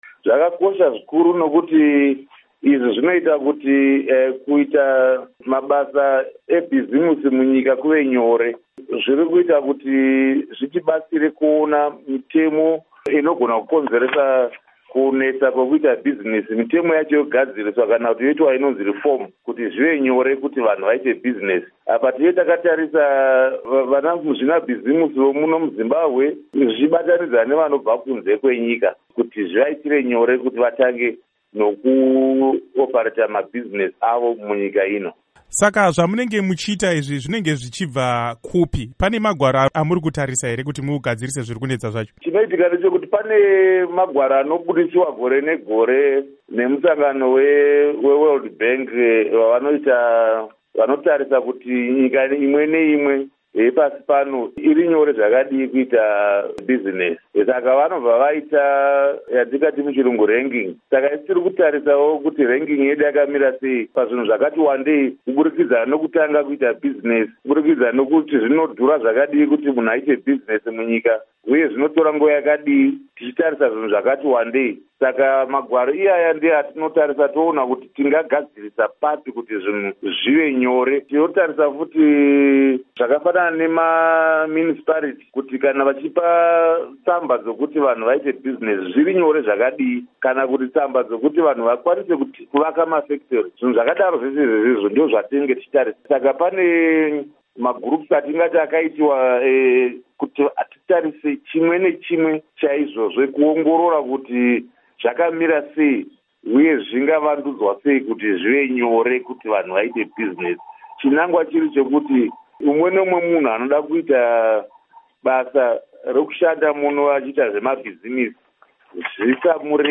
Hurukuro naVaRichard Mbaiwa